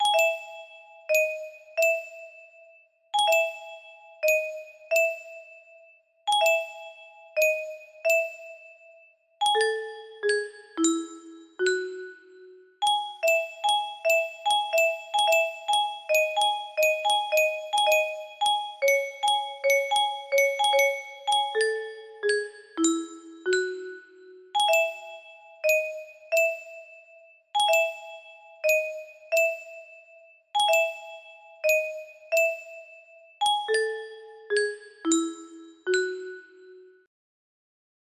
Roses music box melody
Available for use of any sort- just a sweet little melody